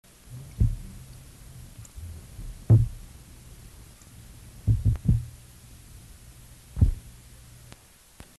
Sounds Made by Morone saxatilis
Type of sound produced low "unk", thumps, clicks, scrapes & escape sounds
Sound production organ swim bladder, teeth
Sound mechanism no associated swim bladder musculature, probably vibration by general body contraction
Behavioural context startle or duress (handling or electric stimulation)
Remark sound always coincident with suddenly increased activity